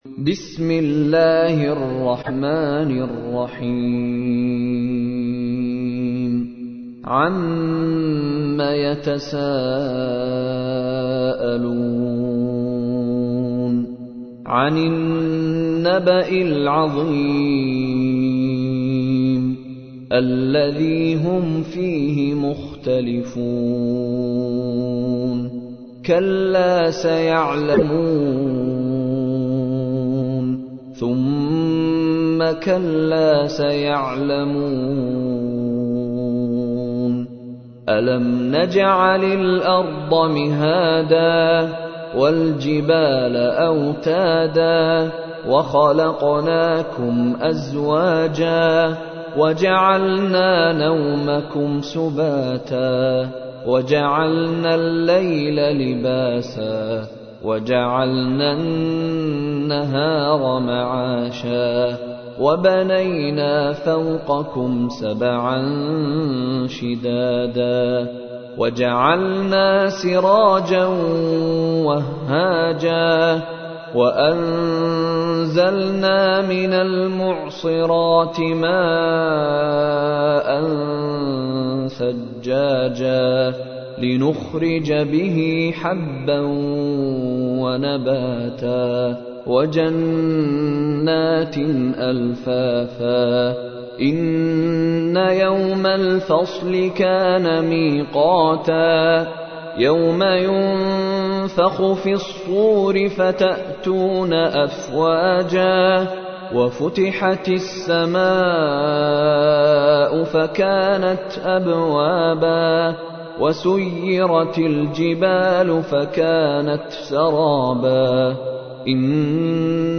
تحميل : 78. سورة النبأ / القارئ مشاري راشد العفاسي / القرآن الكريم / موقع يا حسين